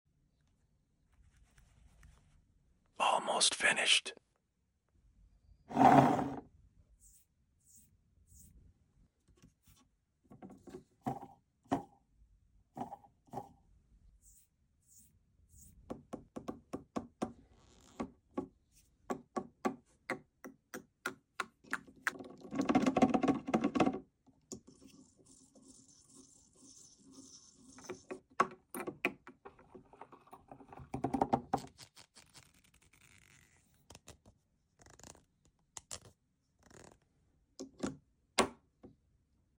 YSL EDP ASMR sound effects free download